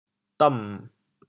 丼 部首拼音 部首 丶 总笔划 5 部外笔划 4 普通话 dăn jǐng 潮州发音 潮州 dom6 白 潮阳 dom6 白 澄海 dong6 白 揭阳 dom6 白 饶平 dom6 白 汕头 dom6 白 中文解释 古同”井“（jǐng）。